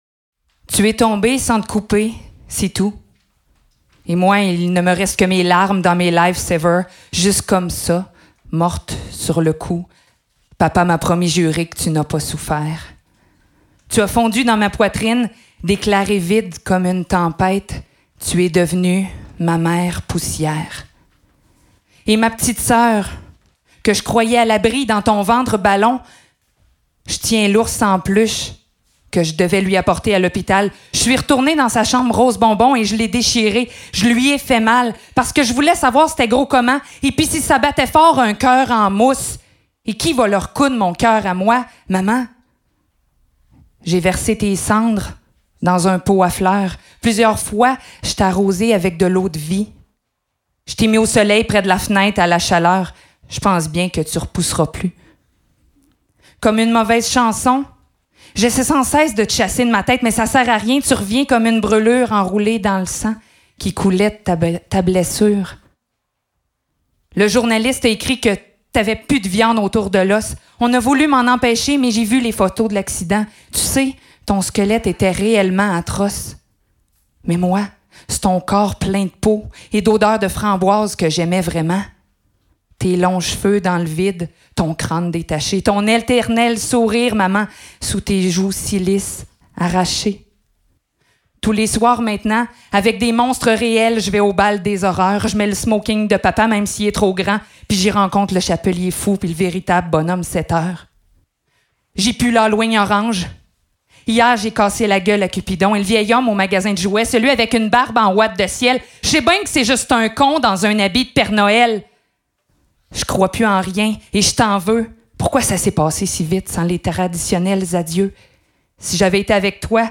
Anthologie des slameuses et slameurs du Québec
Enregistrement au Studio P durant le Printemps des poètes
Enregistrement public et mixage :